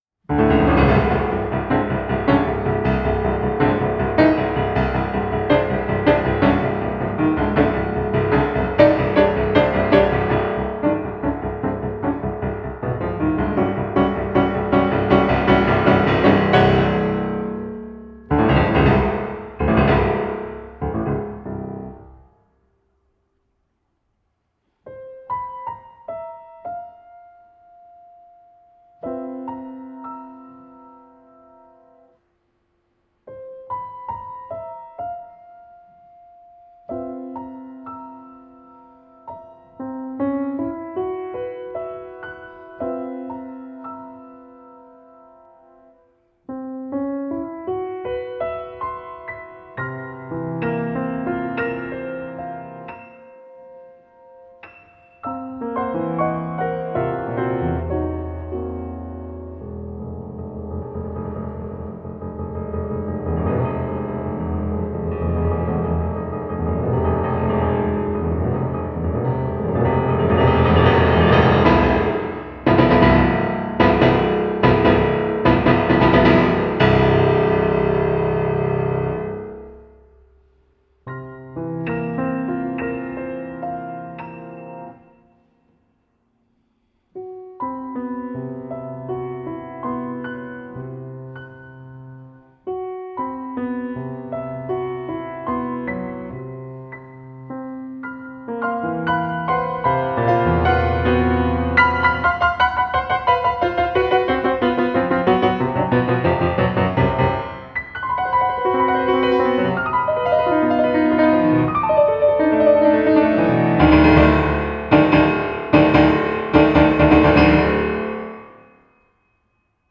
a powerful sense of urgency and hope
piano